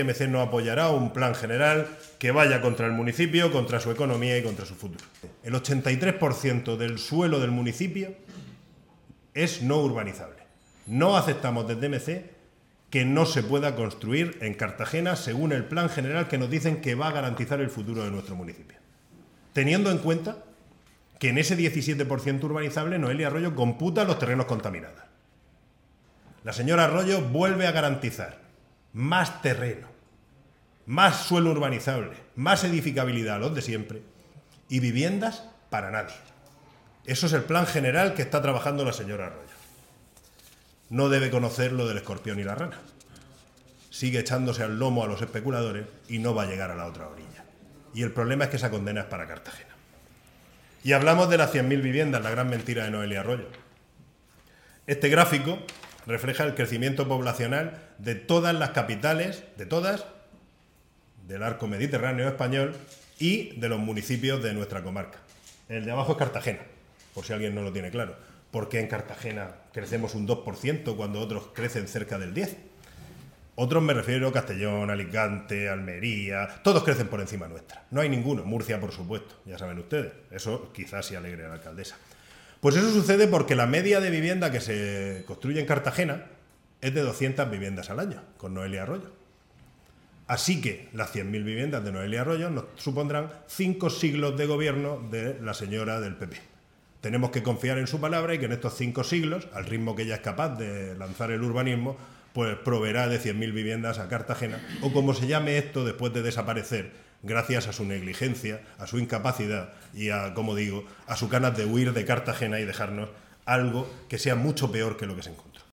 El portavoz y líder de MC Cartagena, Jesús Giménez Gallo, ha comparecido ante los medios de comunicación para denunciar que el nuevo Plan General que pretende aprobar el gobierno de Arroyo el próximo 12 de febrero es profundamente negativo para los intereses de los cartageneros: “Está basado en el miedo, la opacidad y una visión que frena el desarrollo real del municipio mientras concede más derechos a los especuladores de siempre”, ha subrayado.